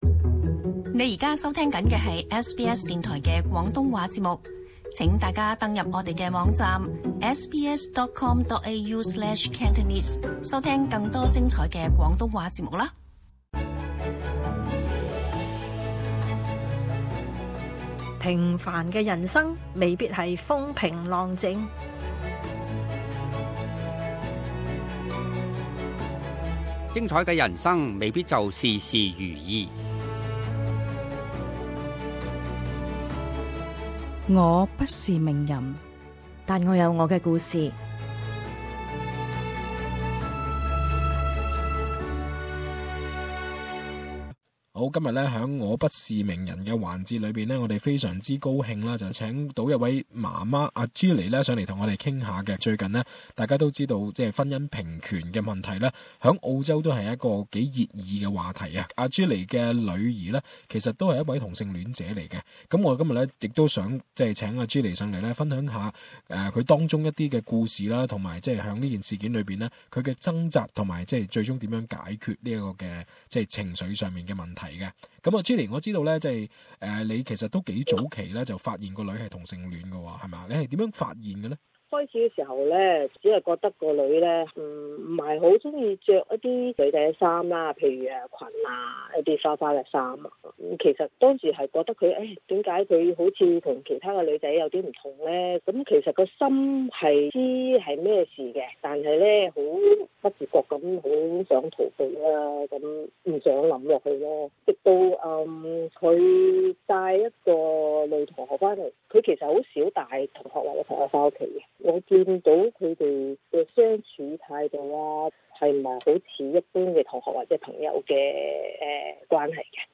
一名華人母親講述發現女兒是同性戀者的經歷，點擊收聽。